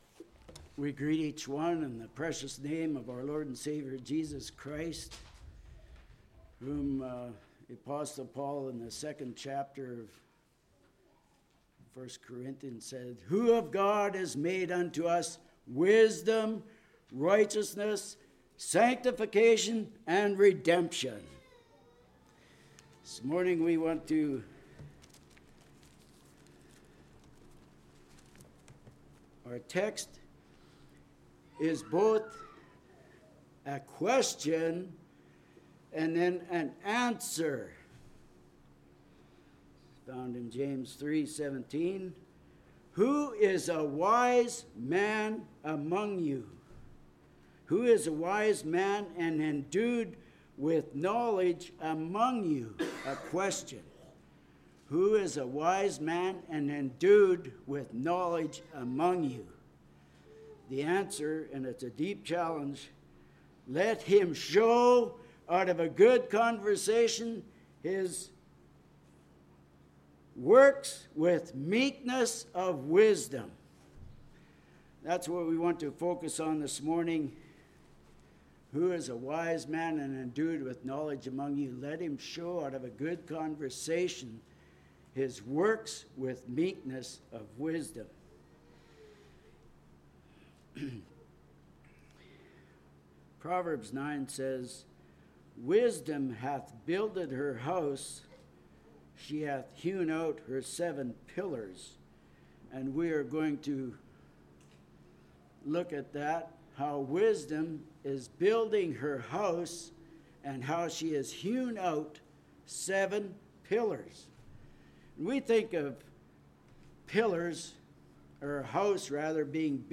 Sermons 11.06.22 Play Now Download to Device Who Is A Wise Man?